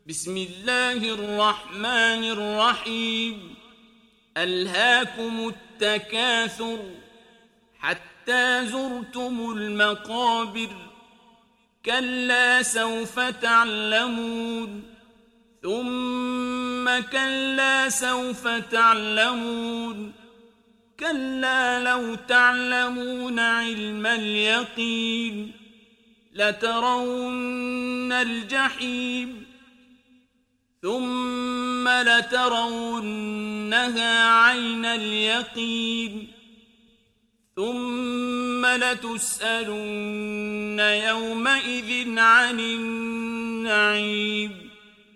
Sourate At Takathur mp3 Télécharger Abdul Basit Abd Alsamad (Riwayat Hafs)
Sourate At Takathur Télécharger mp3 Abdul Basit Abd Alsamad Riwayat Hafs an Assim, Téléchargez le Coran et écoutez les liens directs complets mp3